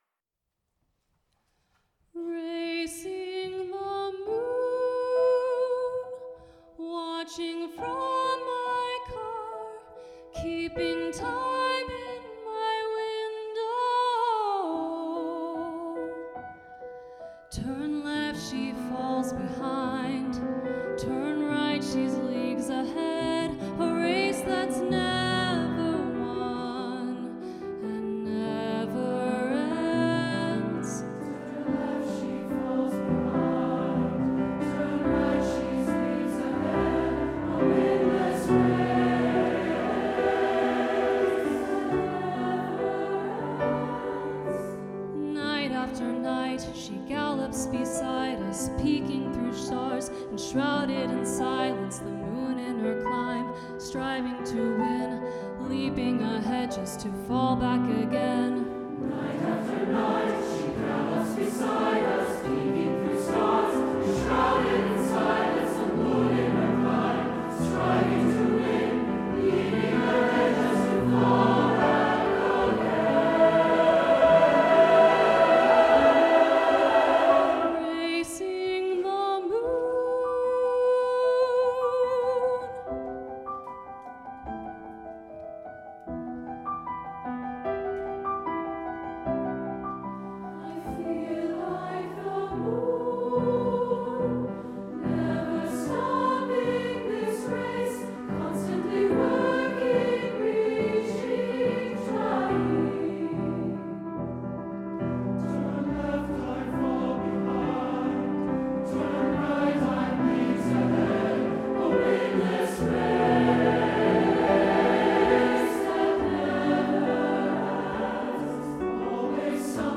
for SATB choir and piano